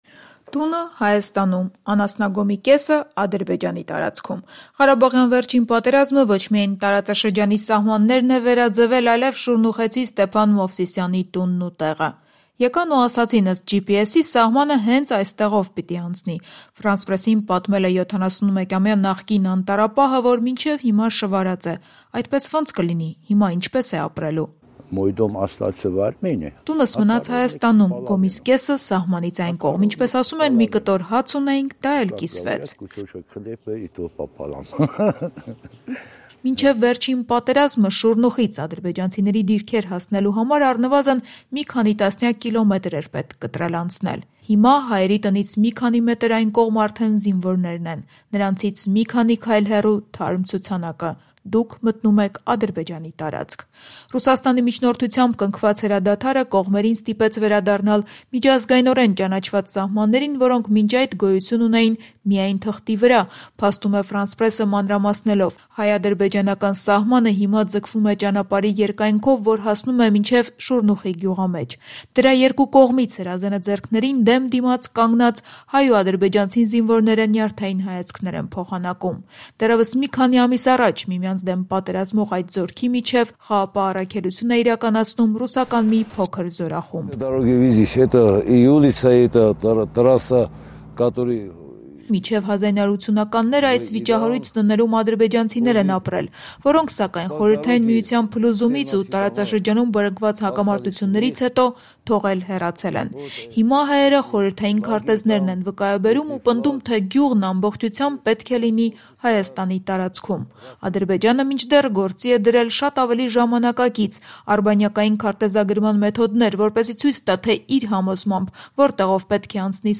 «Կդիմանանք, մինչև կվերադարձնեն մեր հողերը»․ France-Presse-ի հաղորդումը Շուռնուխից
Ռեպորտաժներ